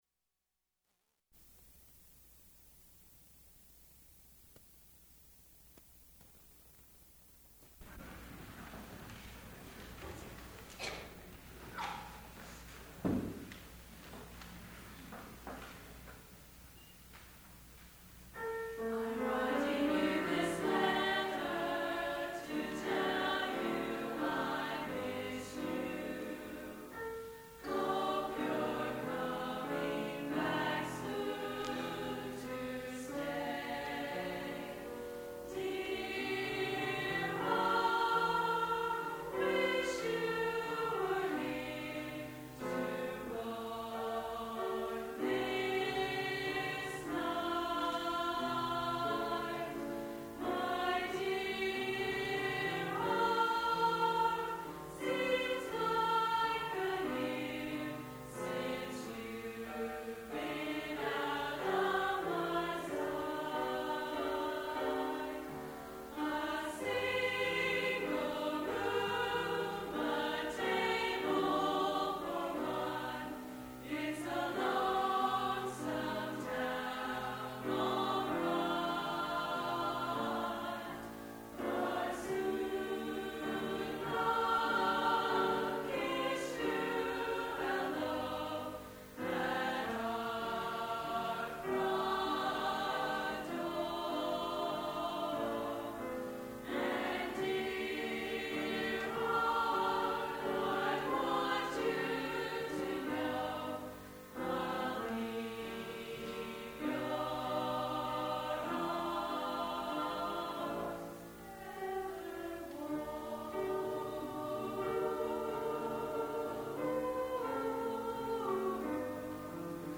Concert of Bethany Nazarene College student groups, the Women's Ensemble and the Plainsmen, recorded on April 29, 1982.